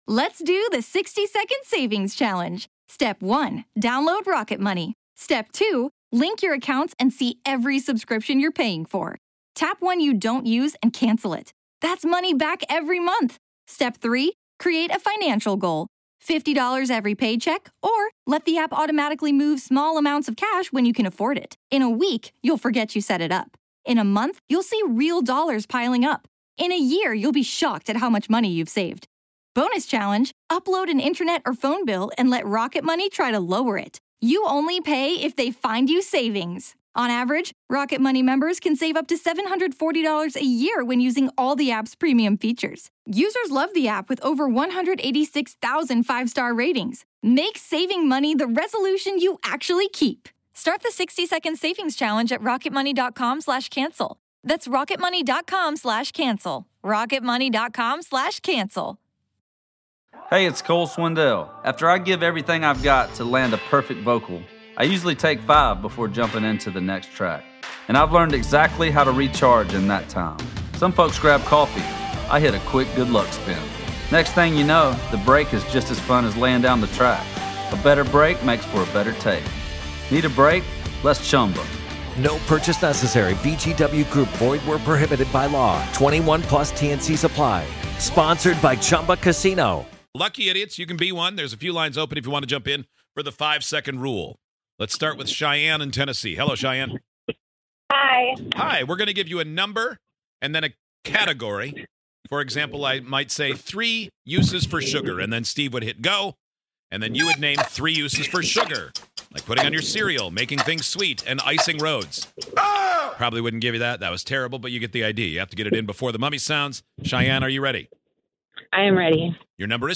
On today's show, you call in, we give you a topic, and you have 5 seconds until the buzzer gives you the boot. Will you beat the buzzer or will the mummy sound first?!